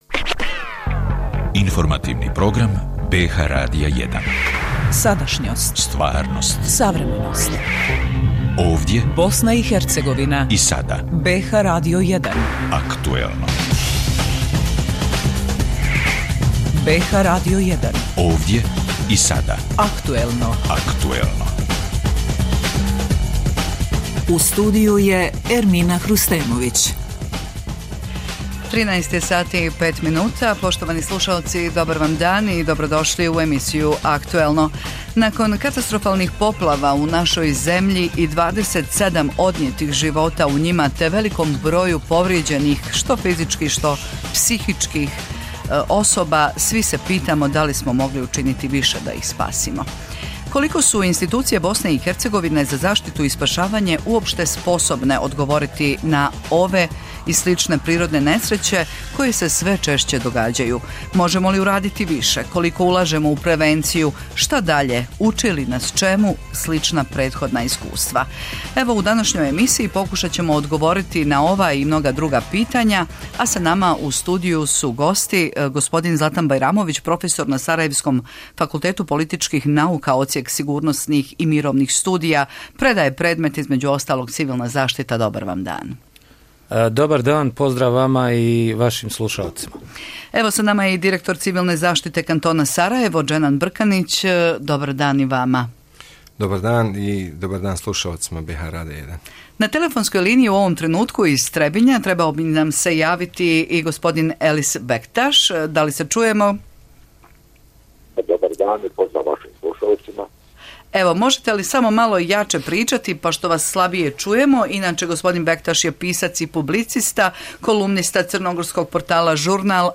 Razgovaramo sa stručnjacima za krizne situacije, direktorima kantonalnih civilnih zaštita.